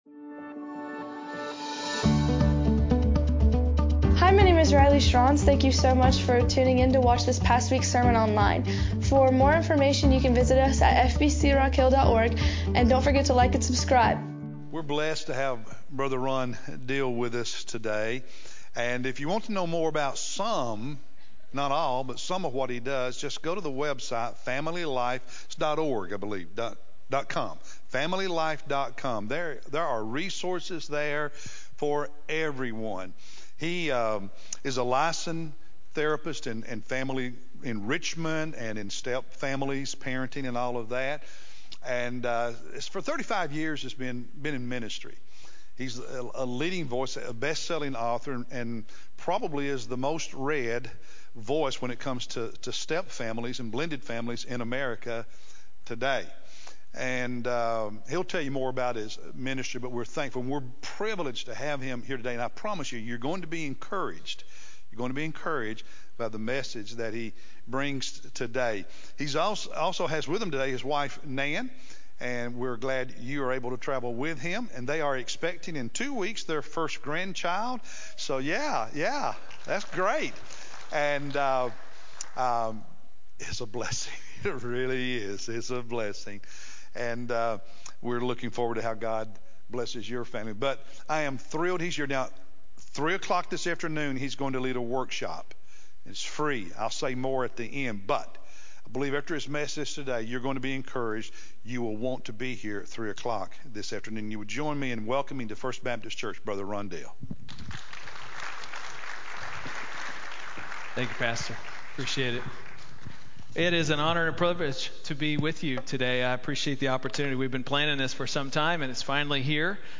August-18-Sermon-CD.mp3